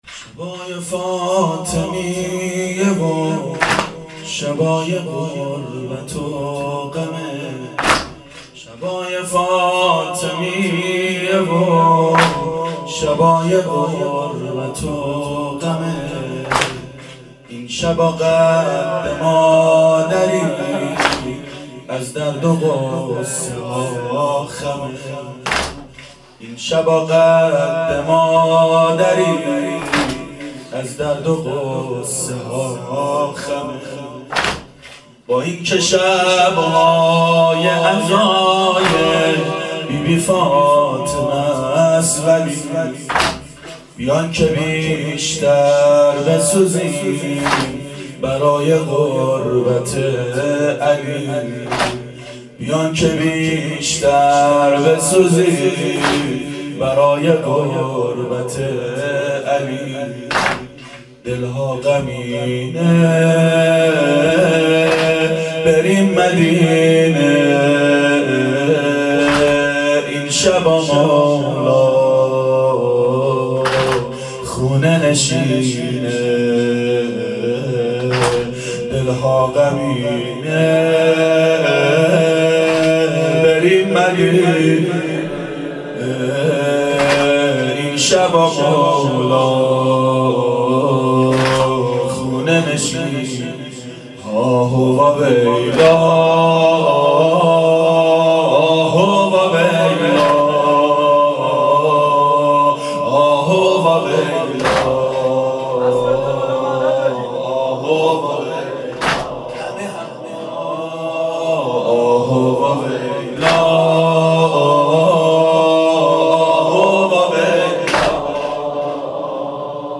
شب شهادت حضرت زهرا سلام الله علیها 1389 هیئت عاشقان اباالفضل علیه السلام